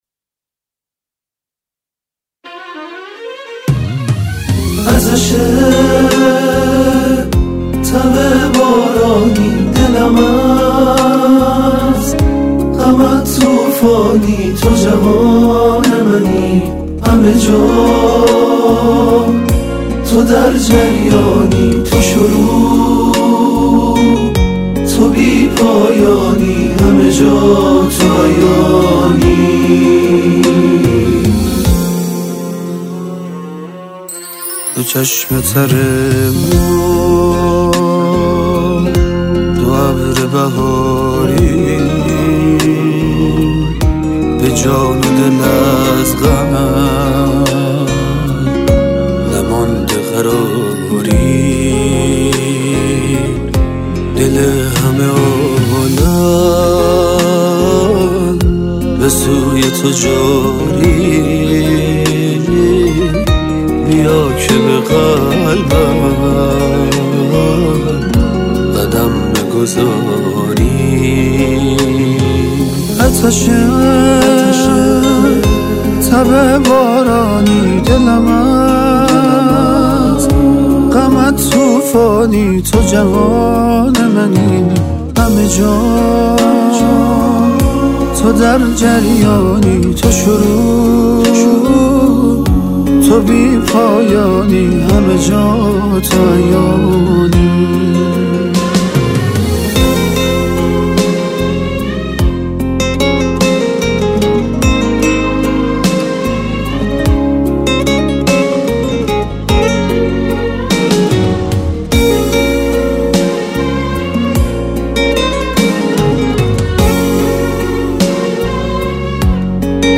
سرودهای امام حسین علیه السلام